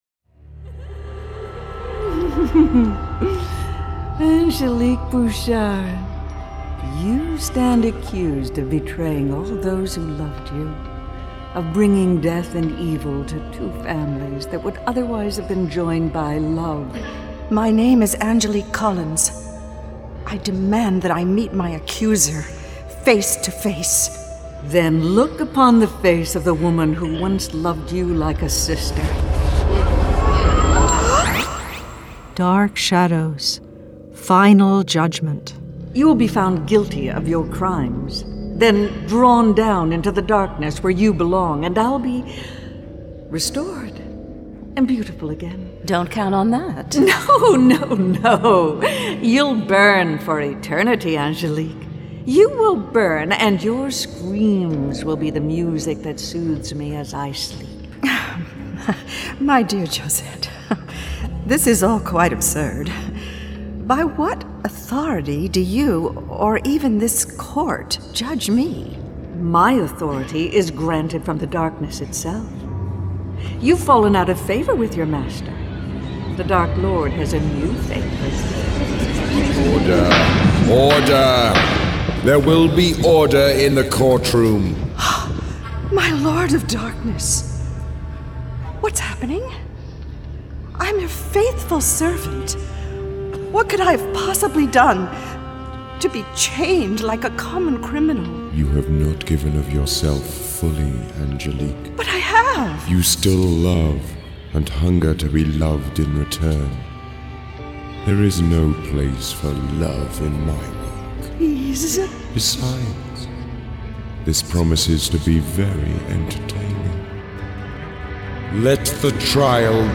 Dark Shadows - Dramatised Readings 10. Dark Shadows: Final Judgement
Starring Lara Parker Kathryn Leigh Scott